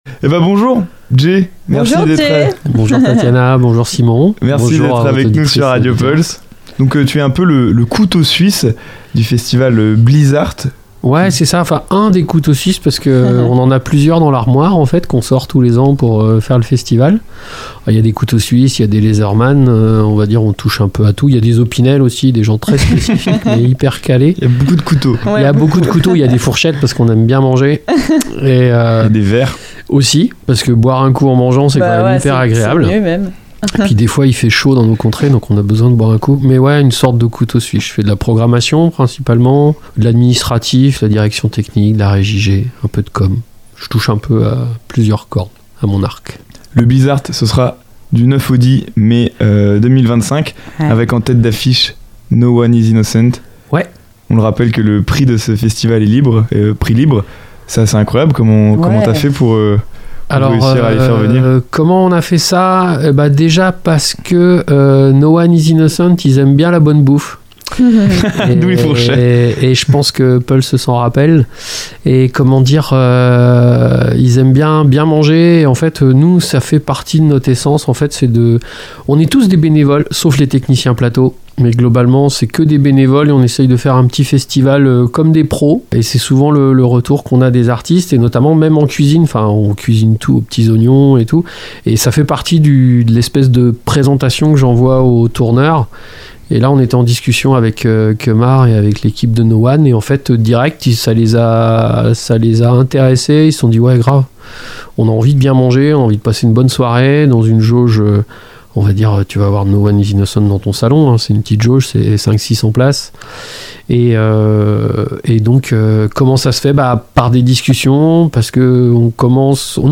Pour en savoir plus sur cet rencontre n'hésitez pas à écouter jusqu'au bout l'interview, et pourquoi pas vous aussi, faire partie des festivaliers !